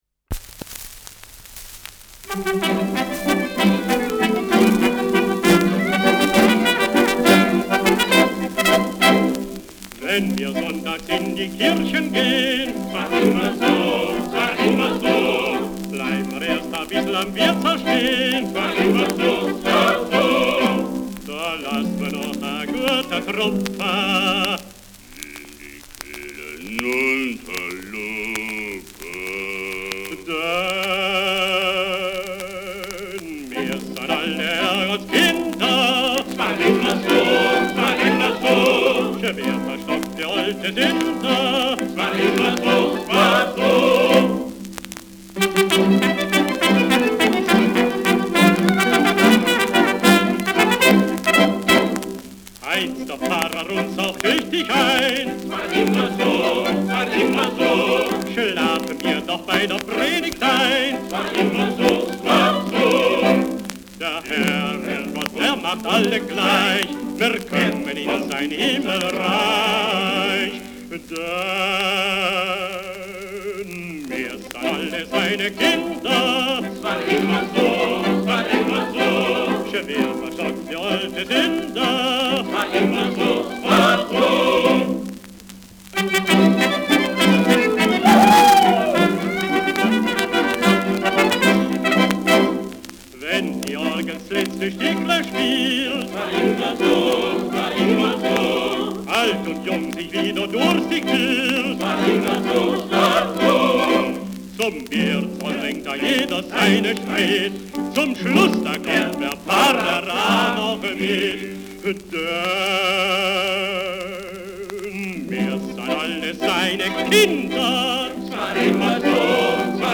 Schellackplatte
leichtes Rauschen : präsentes Knistern
[Berlin] (Aufnahmeort)